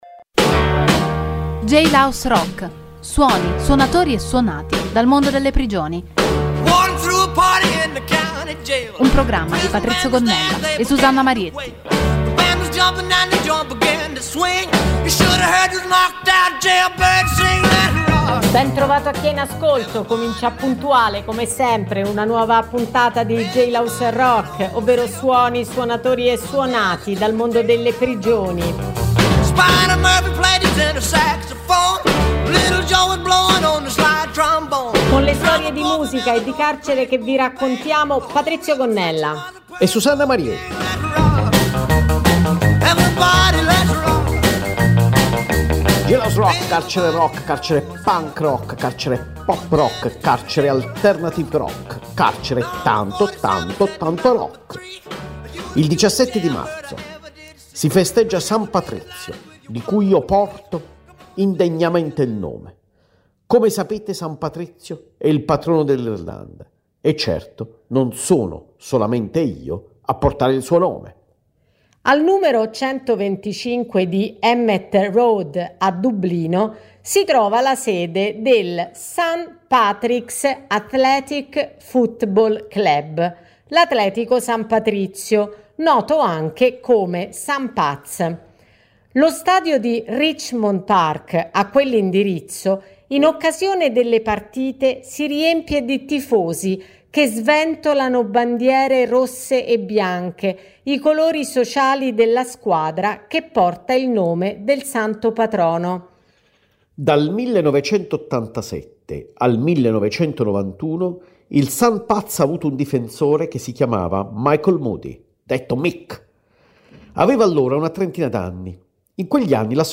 il programma include storie e suoni dal mondo delle prigioni, con la partecipazione di detenuti dei carceri di Rebibbia e Bollate che realizzano un Giornale Radio dal Carcere e cover di artisti.